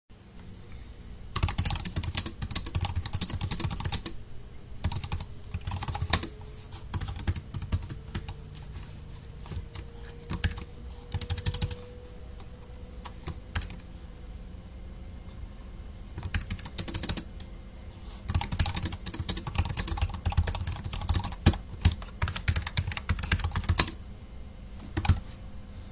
The keyboard is pretty quiet. The clip below gives a sense of what the keyboard sounds like.
Though it lacks adjustable feet, the keyboard operation features light resistance, moderate travel, and quiet operation.